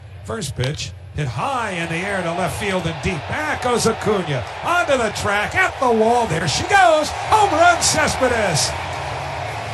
Some back at the track, to the wall, home run call by the numbers.  Never captured the bomb that the home run was.